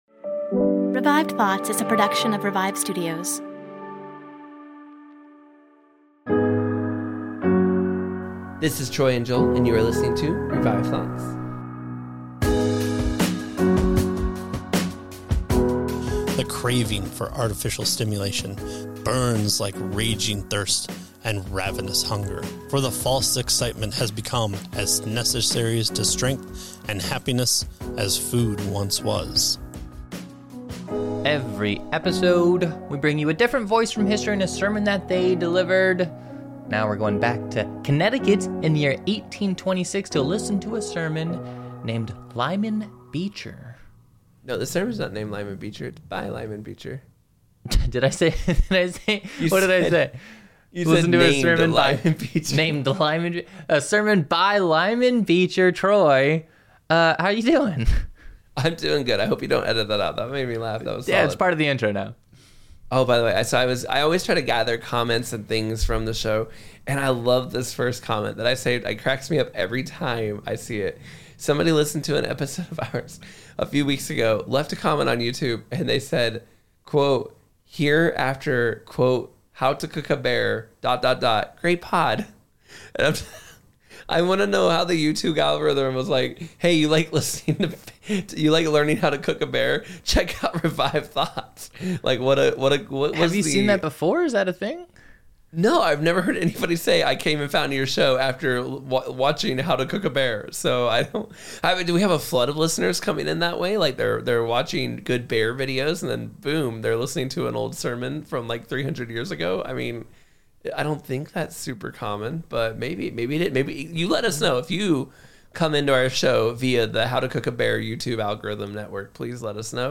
We are bringing history's greatest sermons back to life!